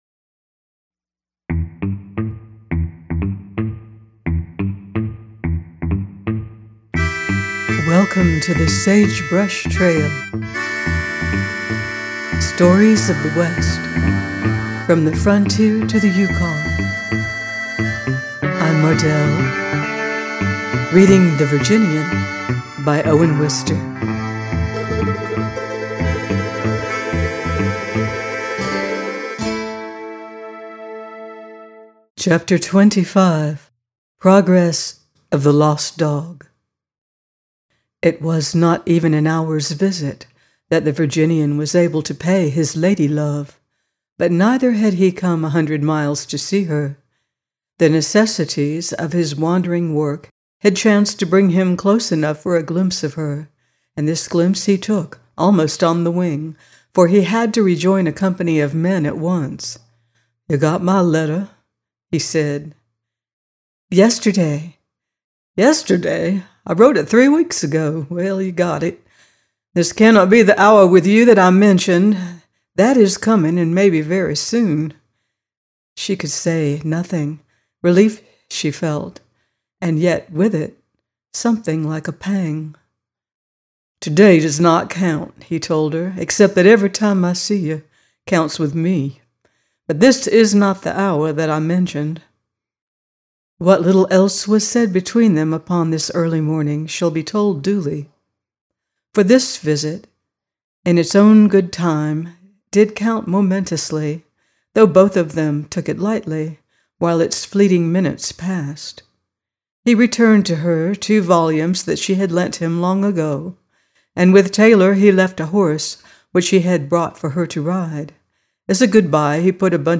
The Virginian 25 - by Owen Wister - audiobook